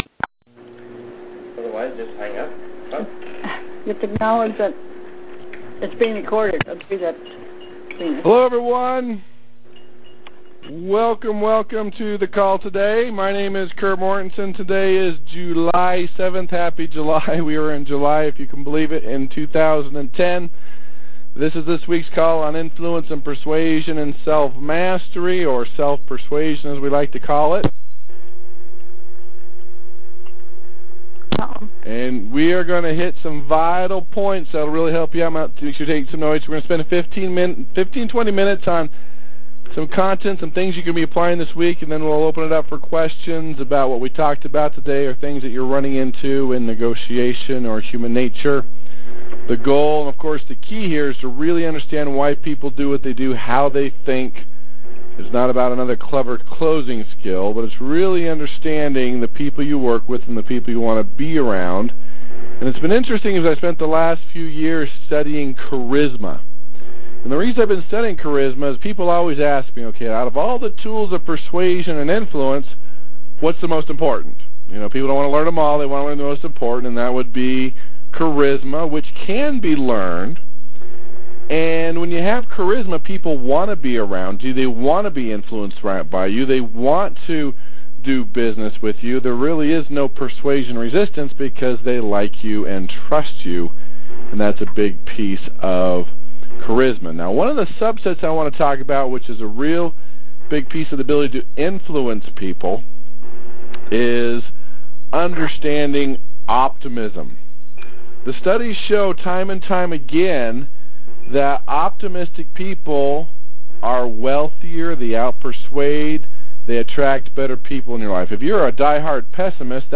‹ Obligation PISSFE › Posted in Conference Calls